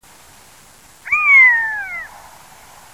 Kurhannik - Buteo rufinus
głosy